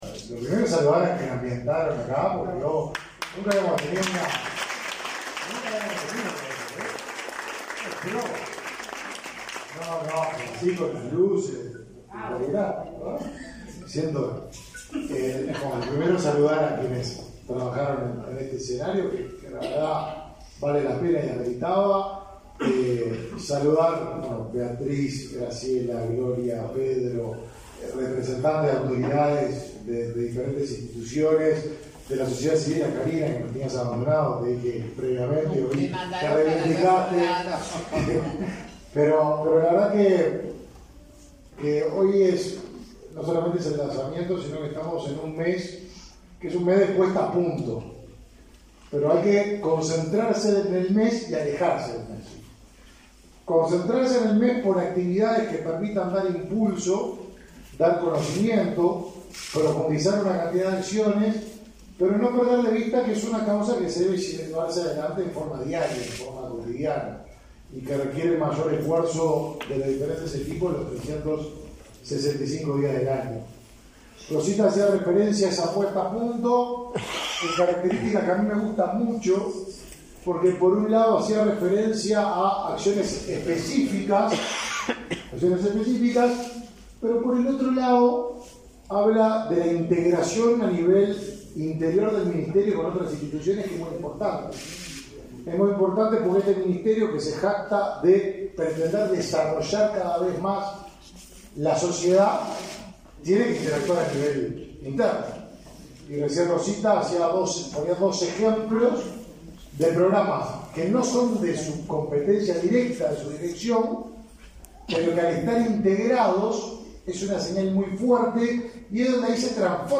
Palabras del ministro del Mides, Martín Lema
Palabras del ministro del Mides, Martín Lema 01/09/2023 Compartir Facebook X Copiar enlace WhatsApp LinkedIn El Ministerio de Desarrollo Social (Mides) realizó, este 1.° de setiembre, el lanzamiento del Mes de la Diversidad, con la presencia de la vicepresidenta de la República, Beatriz Argimón. En el evento, el ministro Martín Lema realizó declaraciones.